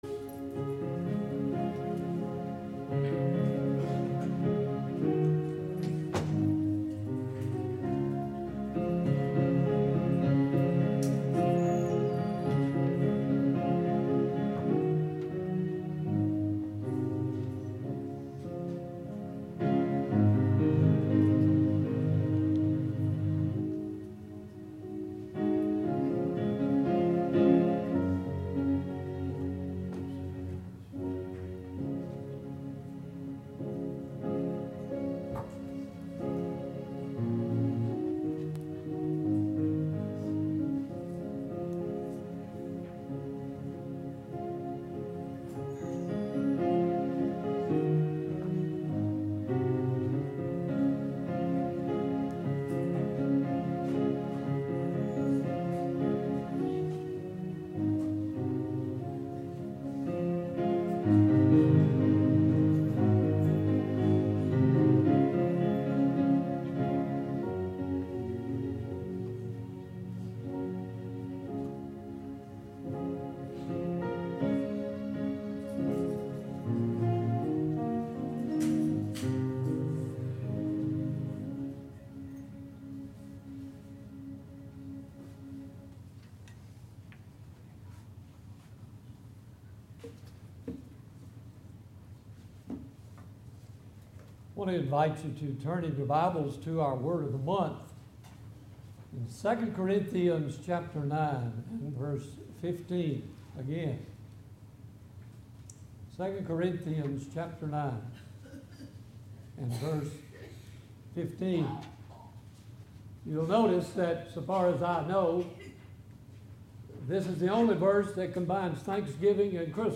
Autaugaville Baptist Church Sermons